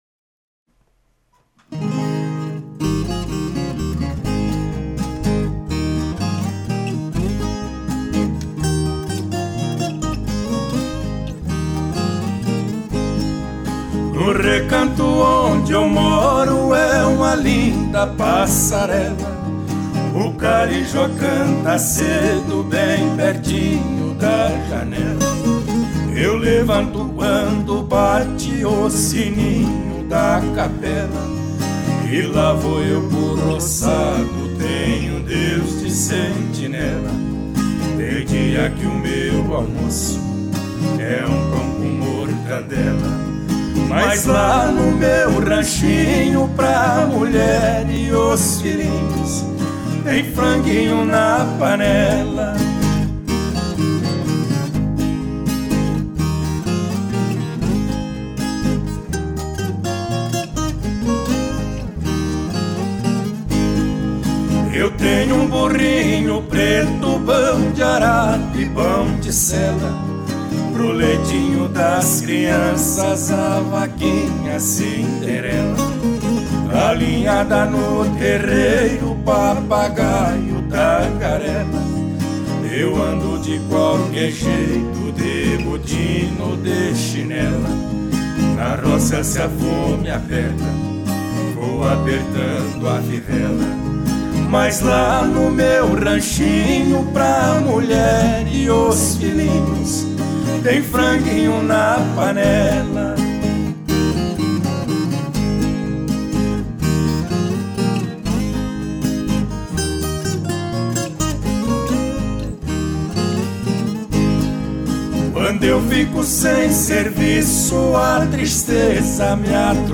Baixo